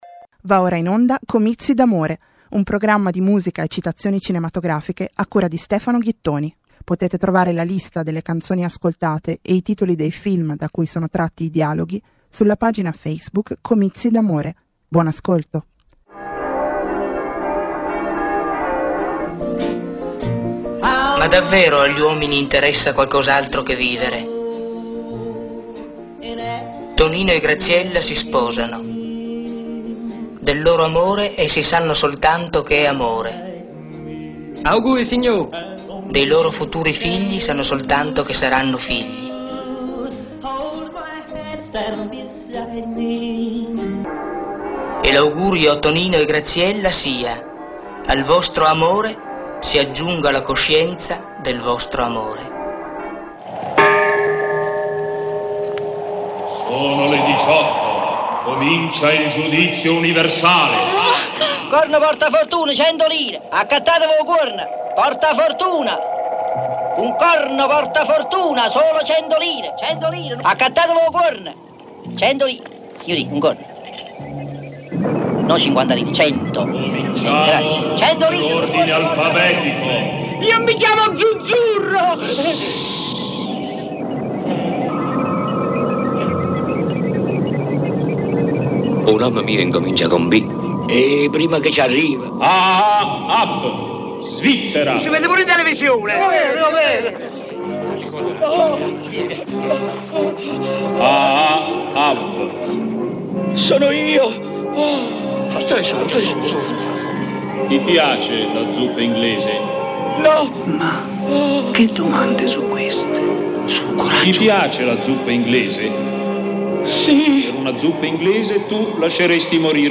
Quaranta minuti di musica e dialoghi cinematografici trasposti, isolati, destrutturati per creare nuove forme emotive di ascolto.